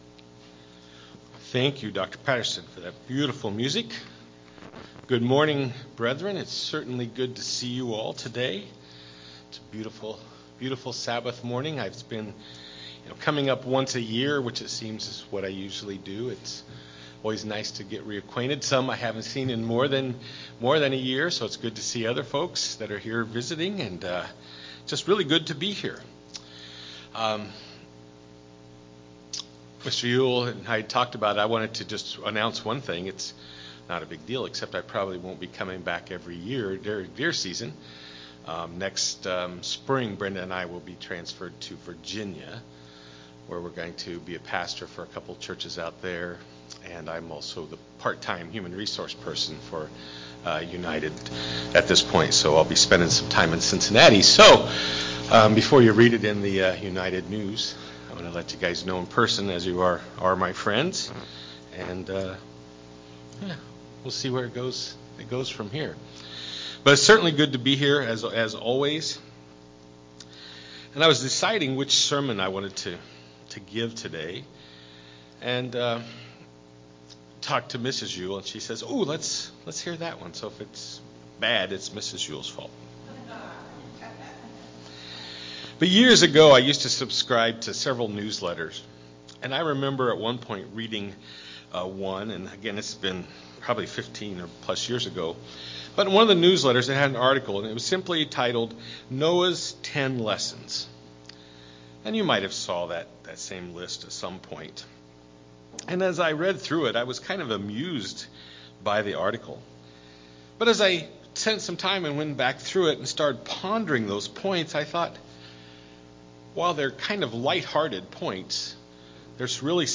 Sermons
Given in Eau Claire, WI